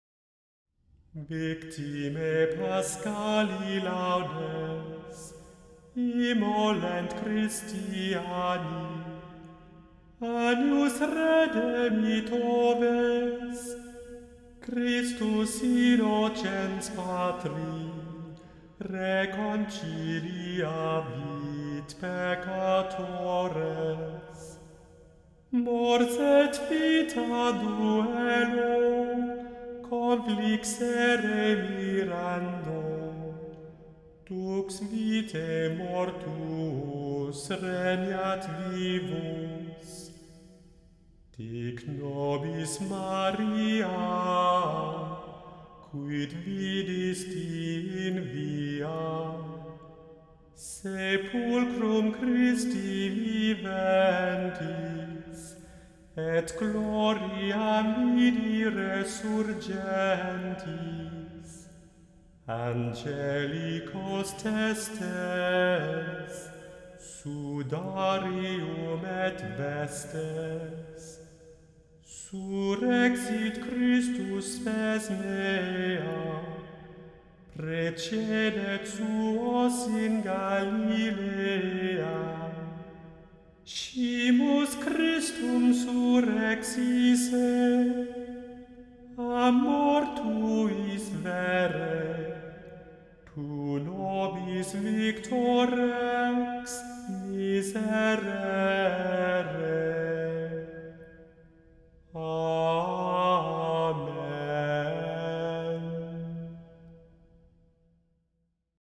The Chant Project – Chant for Today (July 24) – Victimae paschali